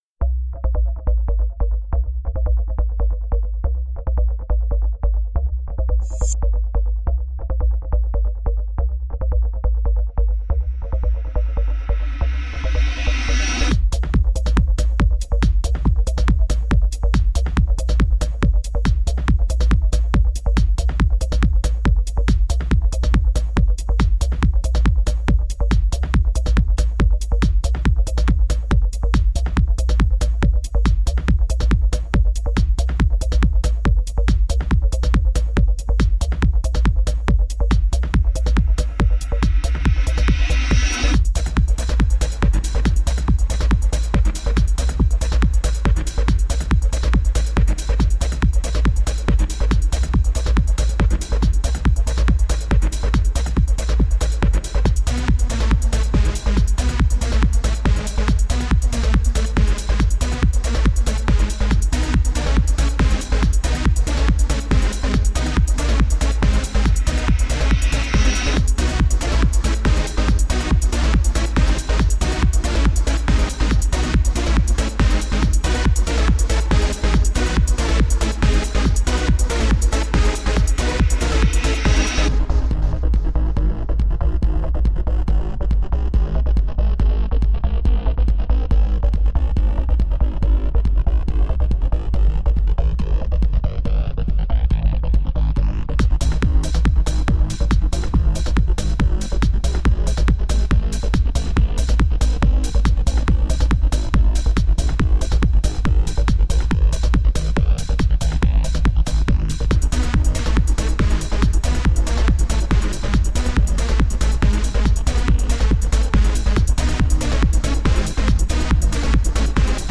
Lose yourself in the electronic.